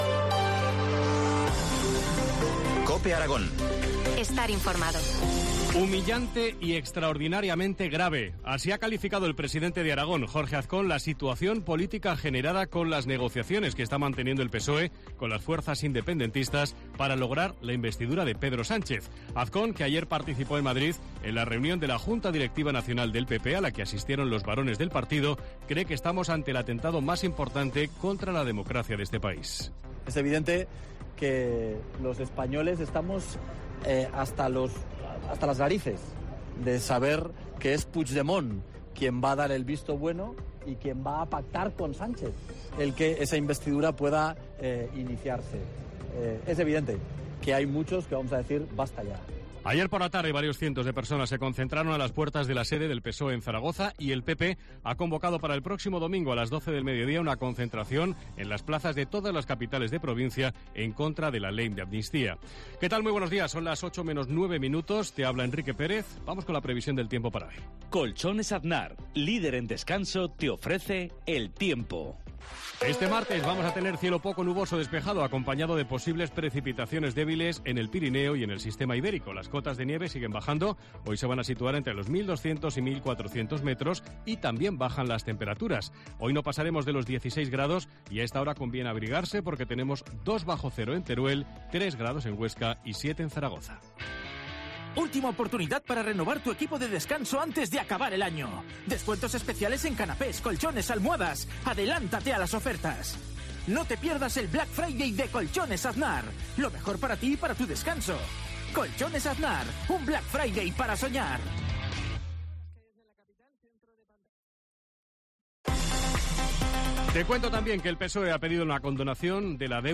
La Mañana en COPE Huesca - Informativo local